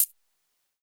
UHH_ElectroHatA_Hit-05.wav